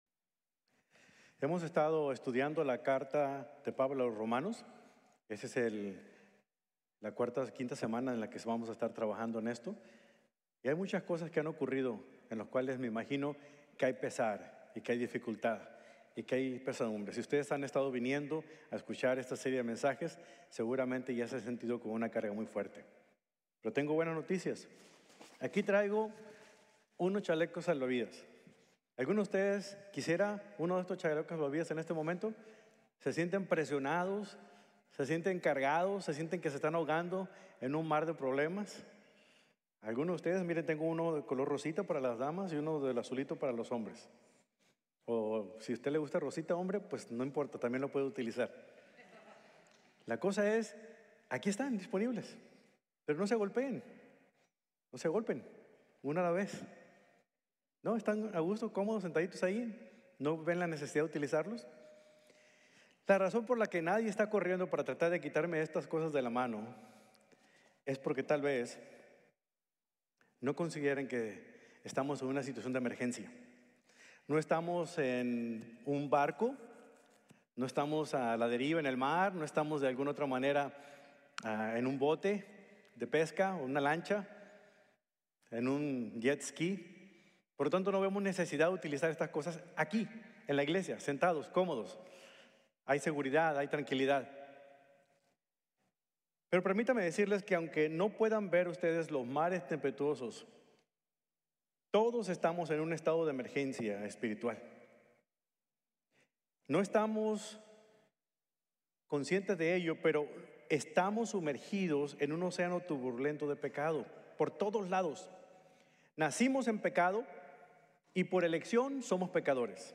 Gracia Asombrosa: Justificación Sin Costo | Sermon | Grace Bible Church